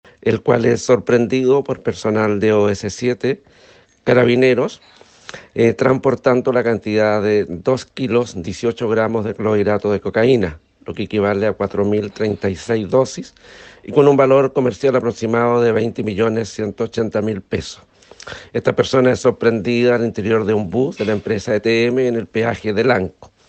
Así lo explicó el Fiscal Alejandro Ríos, quien detalló que la droga incautada equivale a 4.036 dosis, con un avalúo aproximado de veinte millones 180 mil pesos.